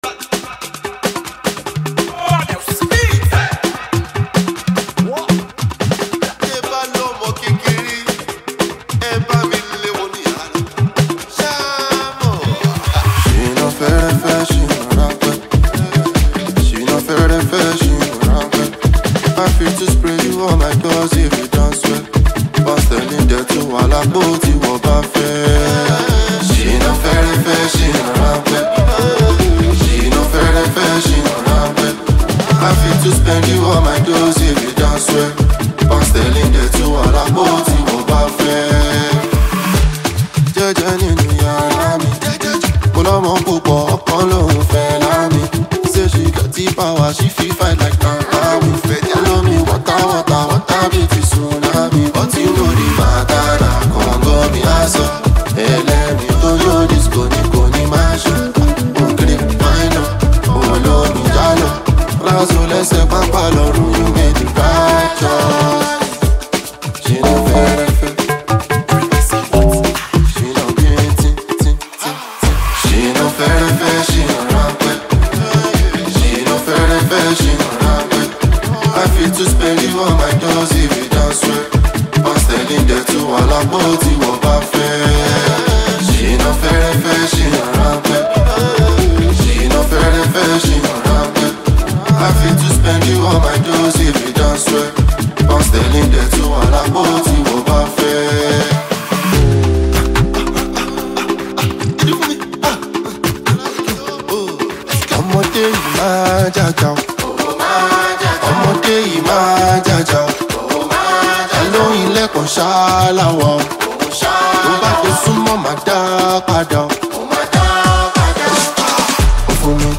infectious rhythms and chant-ready lyrics
Backed by upbeat production and a driving tempo
The catchy chorus and rhythmic flow